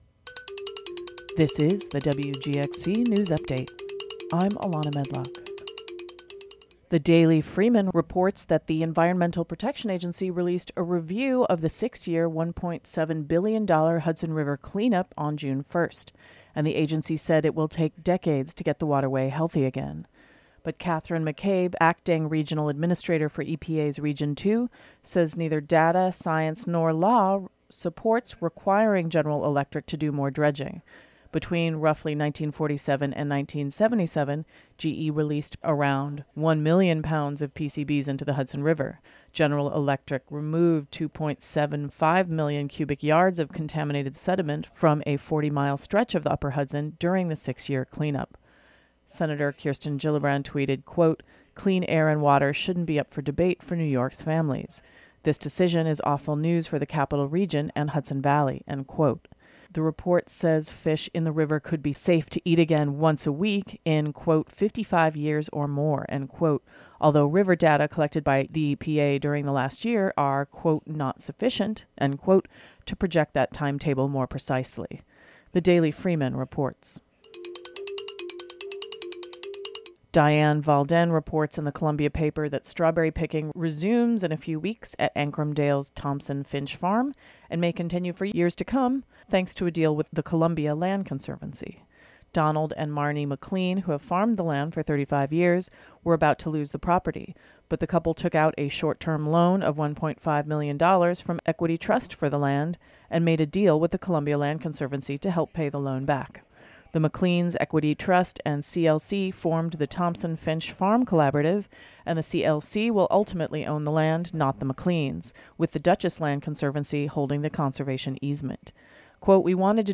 DOWNLOAD or play the audio version of the local news update for Thursday, June 1 (4:05).